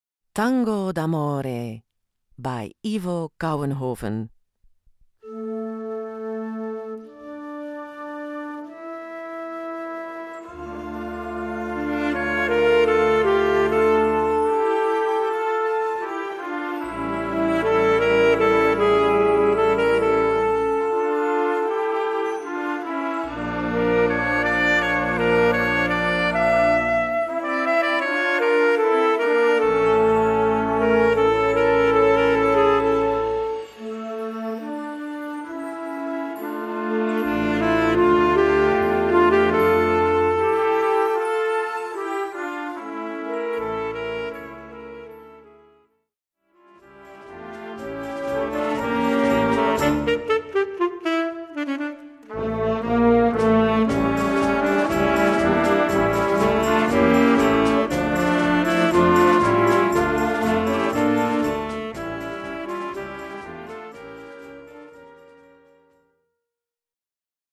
Gattung: Werk für Jugendblasorchester
Besetzung: Blasorchester